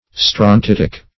Strontitic \Stron*tit"ic\, a.